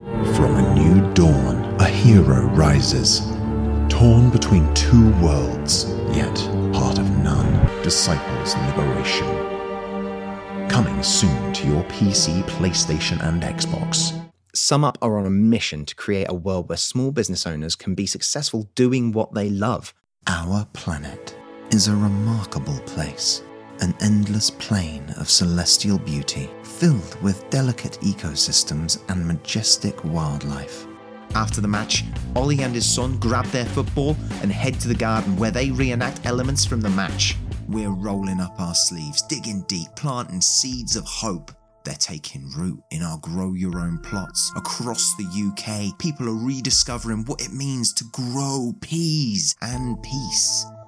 VOICE REEL